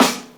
Snare 9.wav